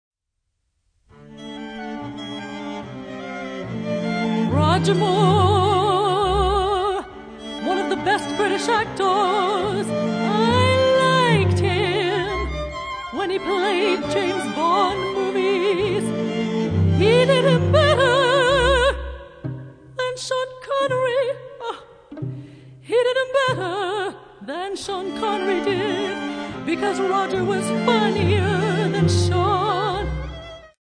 digital opera